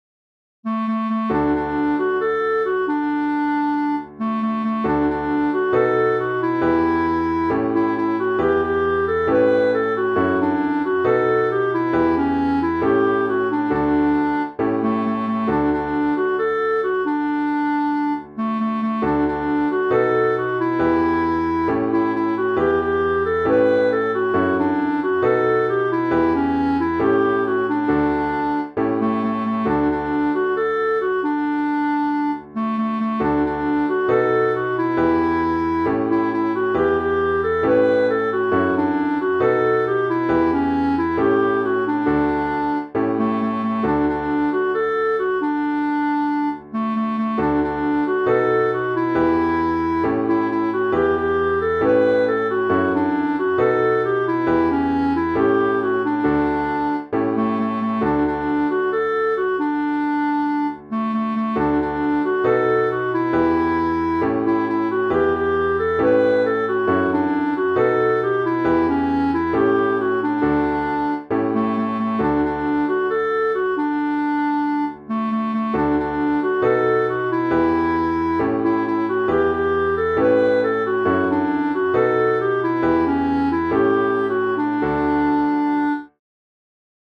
Abra a Roda Tindolele (Cançó tradicional del Brasil)
Interpretació musical de la versió instrumental de la cançó tradicional del Brasil